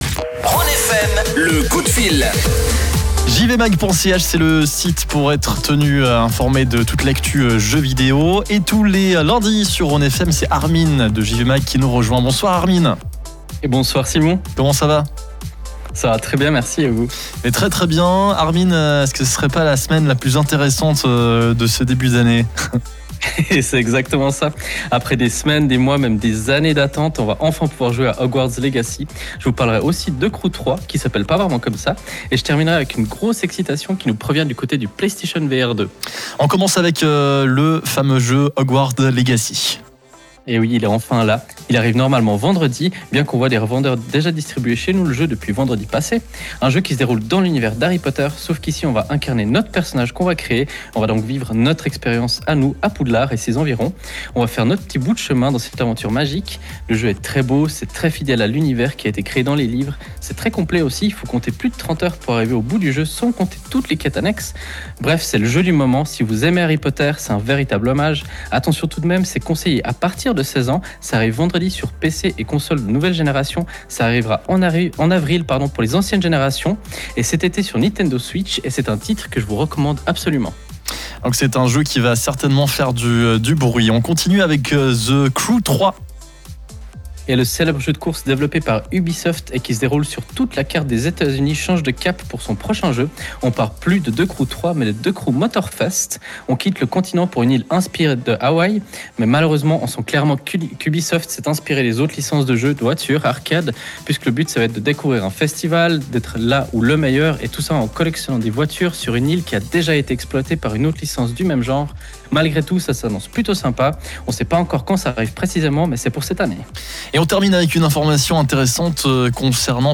Comme tous les lundis, sur la radio Rhône FM, le soir, vous pouvez écouter en direct notre intervention au sujet des jeux vidéo. Cette semaine, au programme; la sortie très attendue de notre côté de Wanted: Dead avec son univers totalement barré, mais simplement addictif. Toujours dans les sorties, on découvre l’arrivée de Wild Hearts, un jeu de chasse qui fait ses premiers pas et qui est soutenu par Electronic Arts, et développé par KOEI Tecmo. On termine évidemment par Nintendo qui a fait le show la semaine dernière avec son émission Direct.